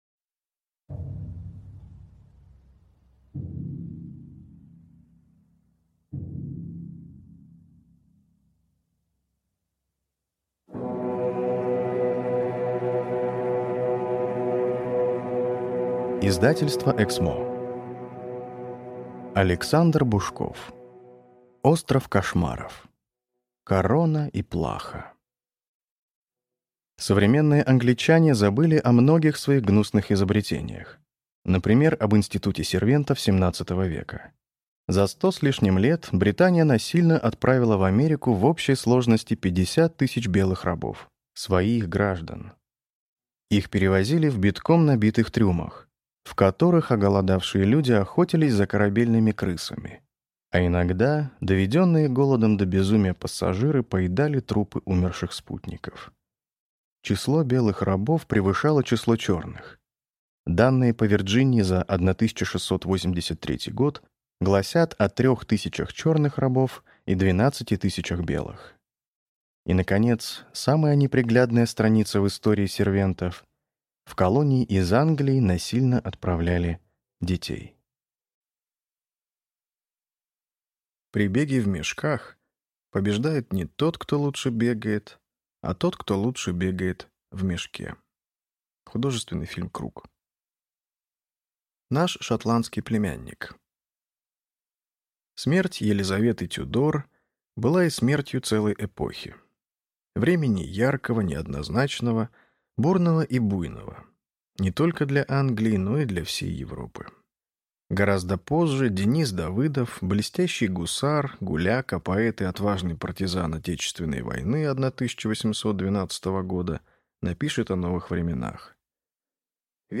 Аудиокнига Остров кошмаров. Корона и плаха | Библиотека аудиокниг